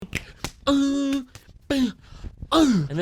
Un clip de sonido de la serie de Netflix 'The Goop Lab' de Gwyneth Paltrow que se hizo popular en tablas de sonido como meme.